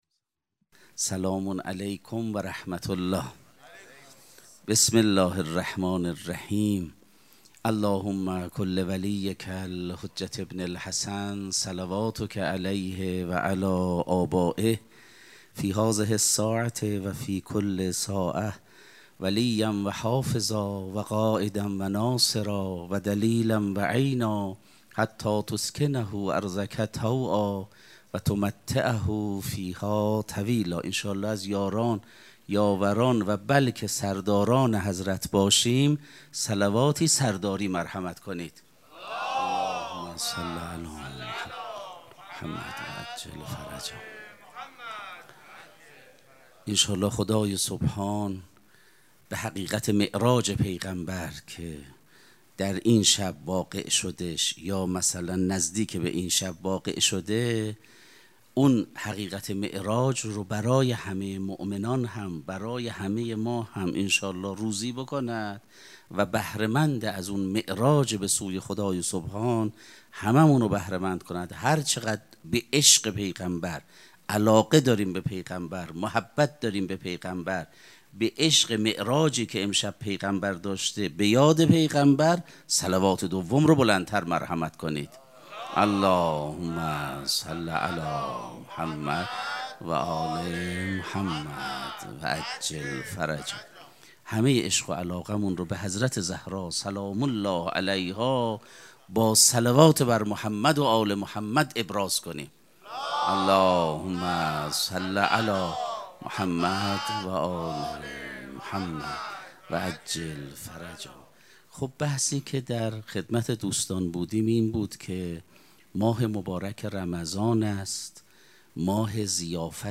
سخنرانی
مراسم مناجات شب هجدهم ماه مبارک رمضان سه‌شنبه‌ ۲۸ اسفند ماه ۱۴۰۳ | ۱۷ رمضان ۱۴۴۶ حسینیه ریحانه الحسین سلام الله علیها